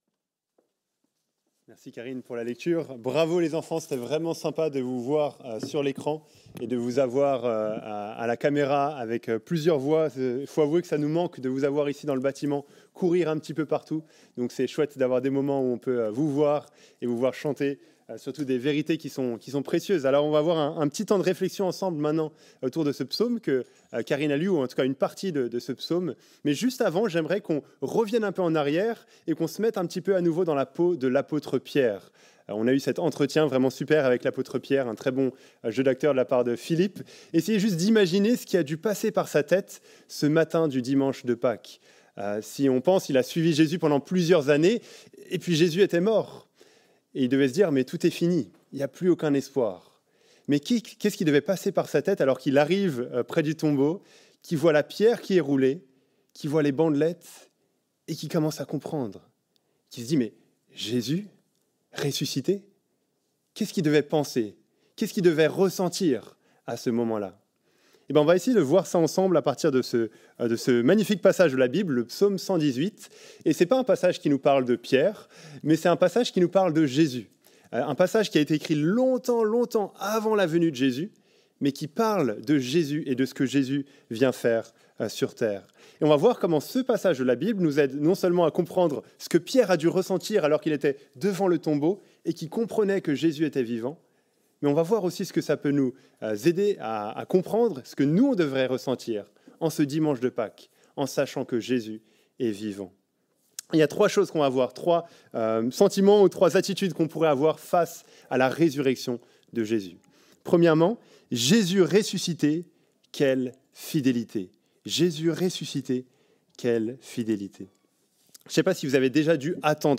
culte-du-4-avril-2021-epe-bruxelles-woluwe.mp3